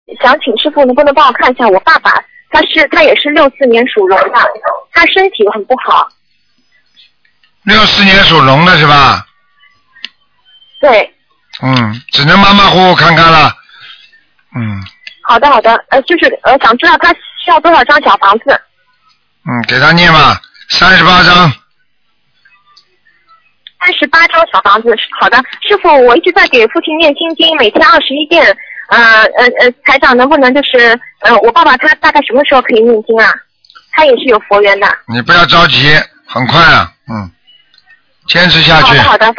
目录：2013年01月_剪辑电台节目录音集锦